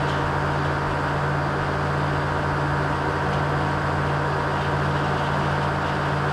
agricultural-tower-rotation-loop.ogg